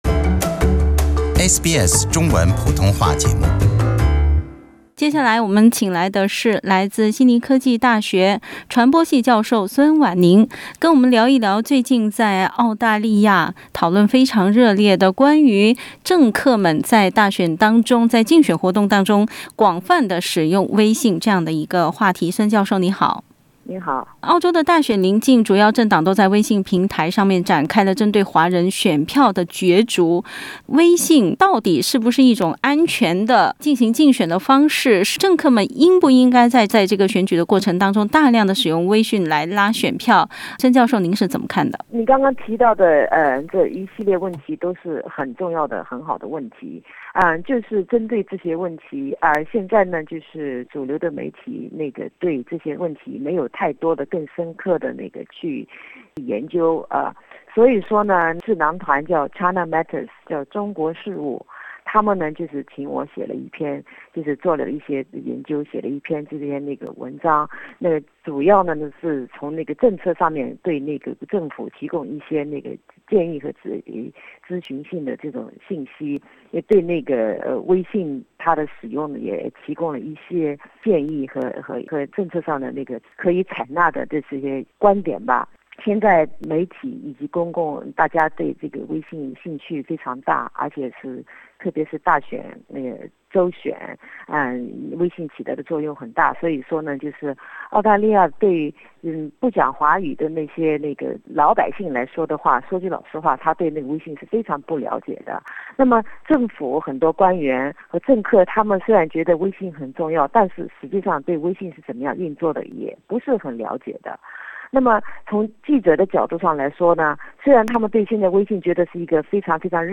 听众朋友，欢迎收听SBS普通话电台制作的特别节目 - 系列专访“微信与选举”。